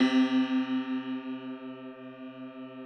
53r-pno06-C1.aif